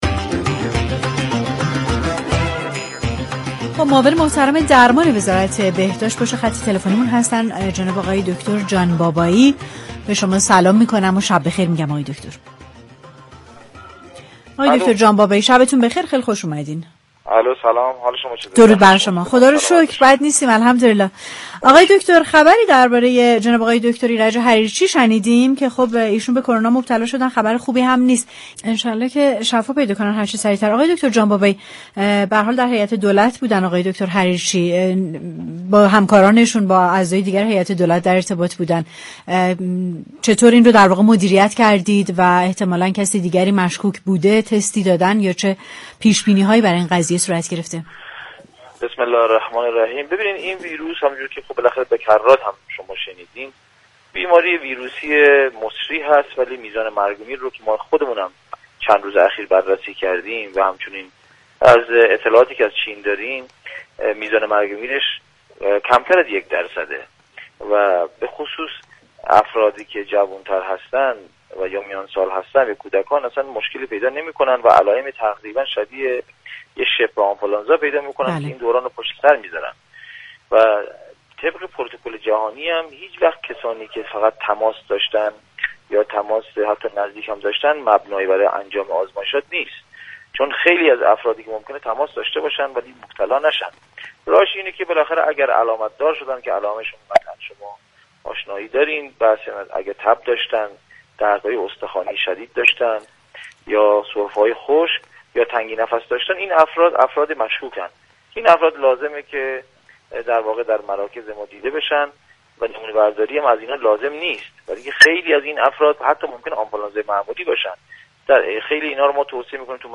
در ابتدای گفتگوی برنامه‌ی تهران من با دكتر قاسم جان بابایی ، صحبتهای حریرچی، معاون وزیر بهداشت از آنتن رادیوتهران پخش شد.